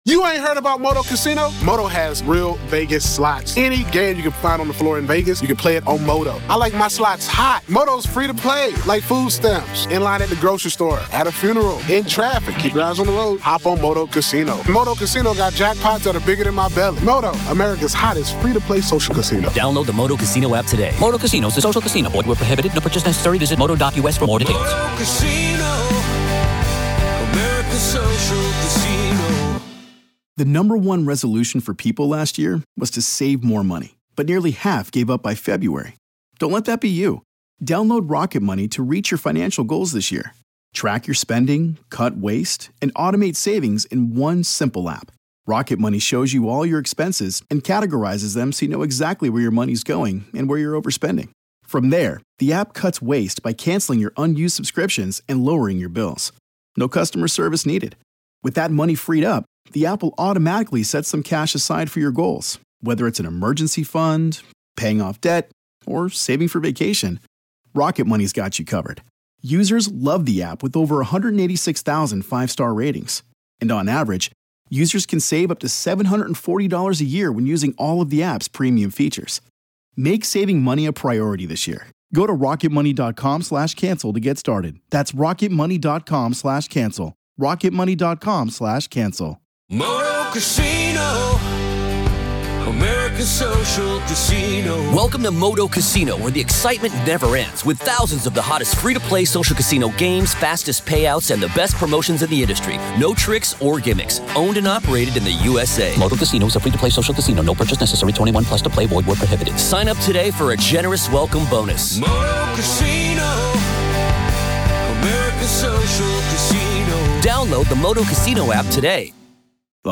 From the best supernatural & paranormal podcast, Real Ghost Stories Online! Haunting real ghost stories told by the very people who experienced these very real ghost stories.